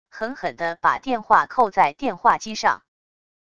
狠狠的把电话扣在电话机上wav音频